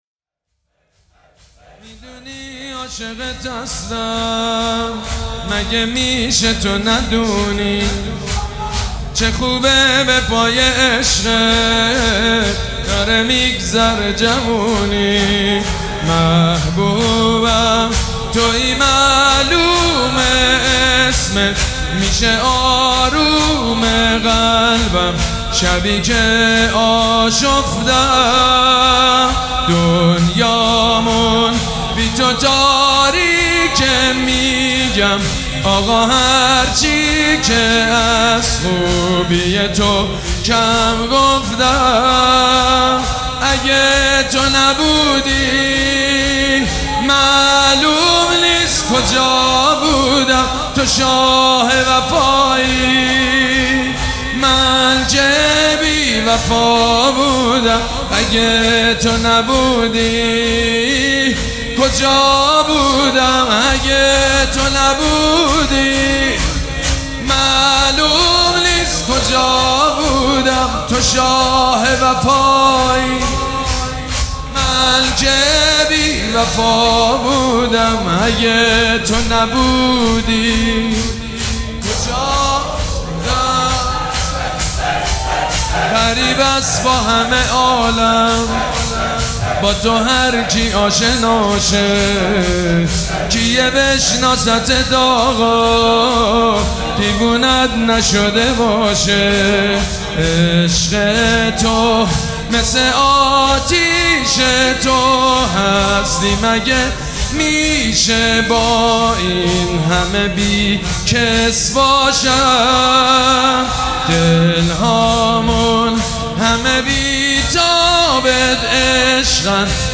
شور
مداح
حاج سید مجید بنی فاطمه
مراسم عزاداری شب شهادت حضرت زهرا (س)